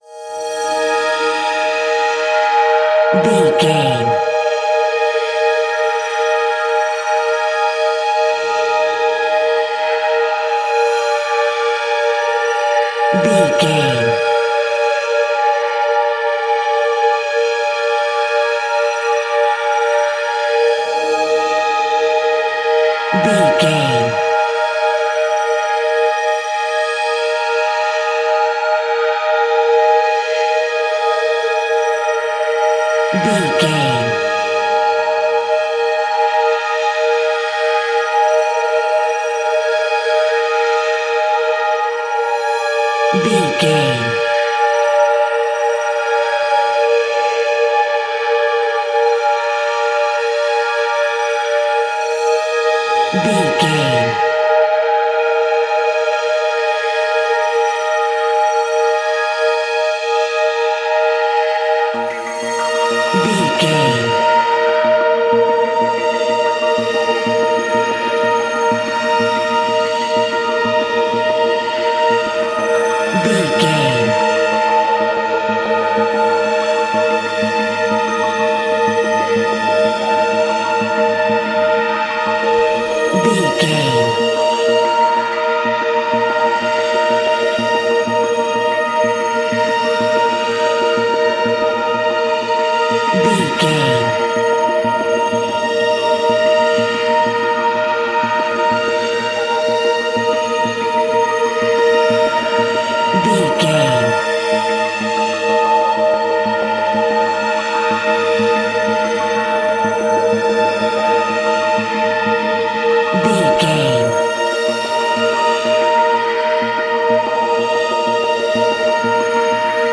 Thriller Moving Pads.
Aeolian/Minor
E♭
Slow
scary
ominous
dark
haunting
eerie
ethereal
synthesiser
horror music